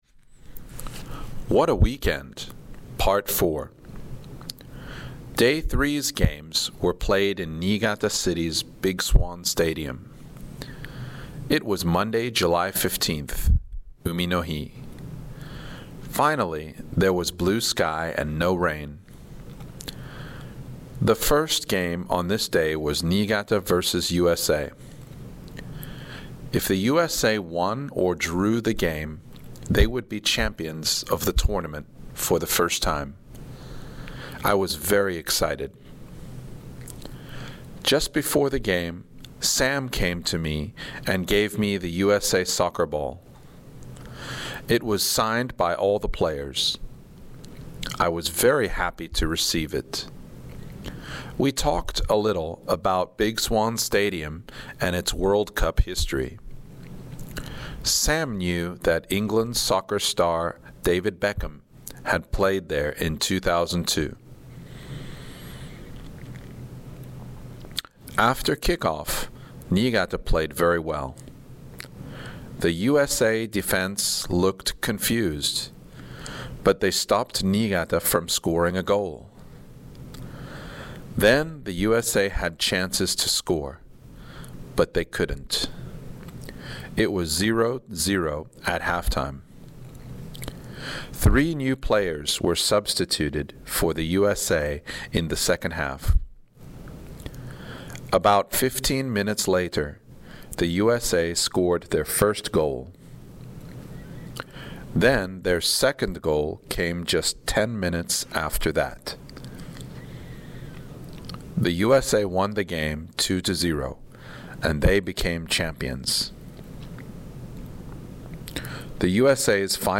69 What a Weekend! (part 4) ＲＥＡＤＩＮＧ
(fast)